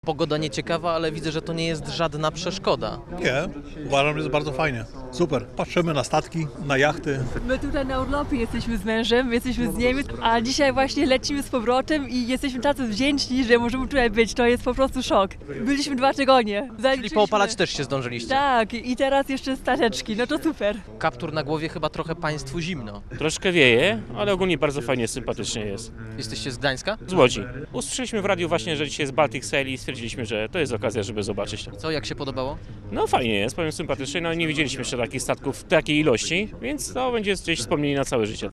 Z obserwatorami widowiska na brzeźnieńskim molo rozmawiał